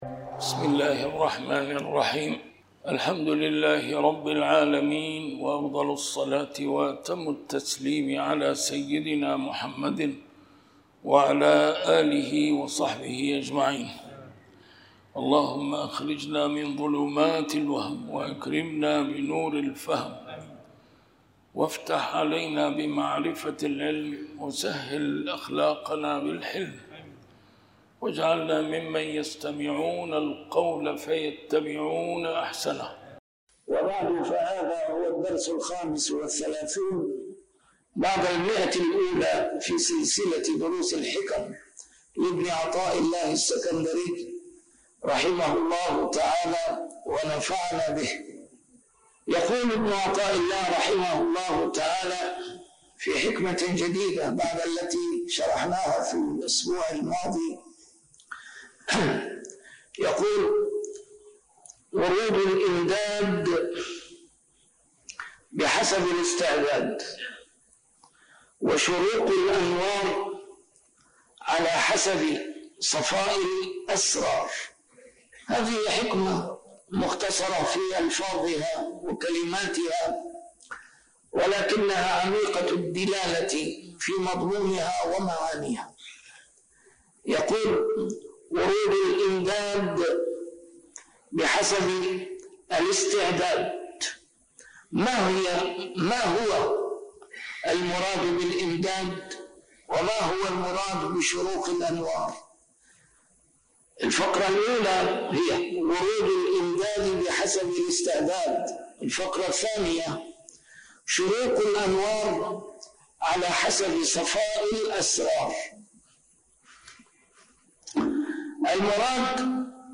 A MARTYR SCHOLAR: IMAM MUHAMMAD SAEED RAMADAN AL-BOUTI - الدروس العلمية - شرح الحكم العطائية - الدرس رقم 135 شرح الحكمة 113